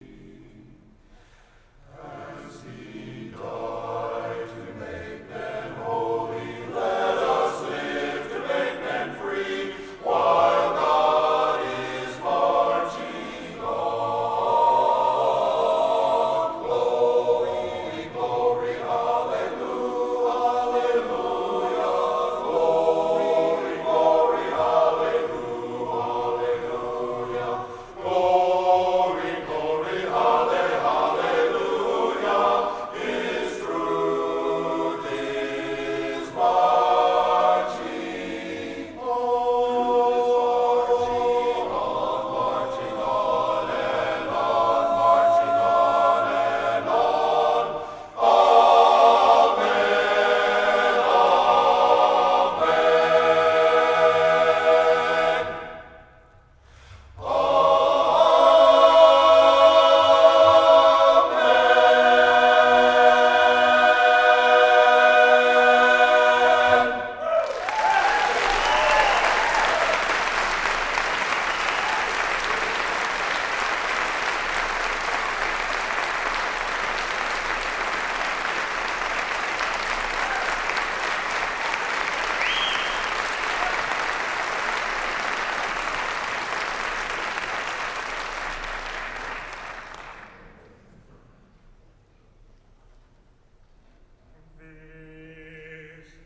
I recently helped provide sound reinforcement for a local benefit concert.
The performers included the Thoroughbreds (a 20-member male chorus), the Don Kreckel Orchestra and the Ladies for Liberty (an Andrews Sisters – like trio).
The challenge of this event would be in achieving sufficient acoustic gain for the choir mics and orchestra overheads in the 1200-seat auditorium. Such distance-miking techniques always presents feedback problems.
Amplified_Male_Chorus_90degCardioids.mp3